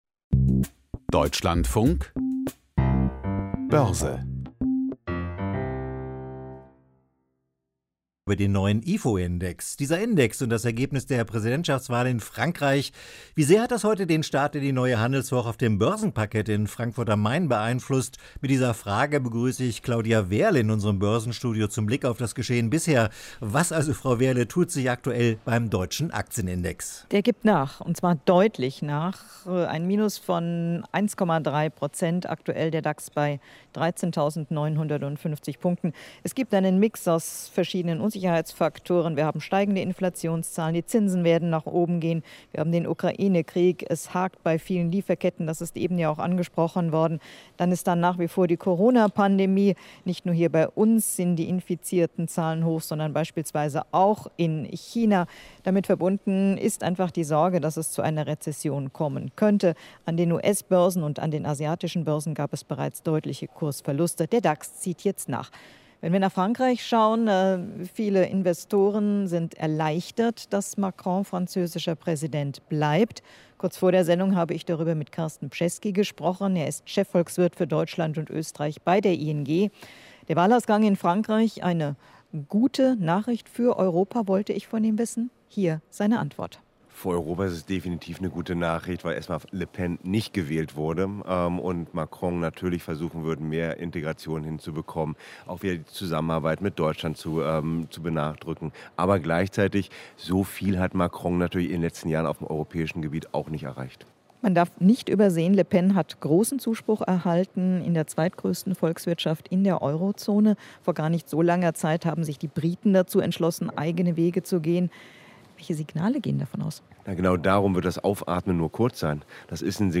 Börsengespräch aus Frankfurt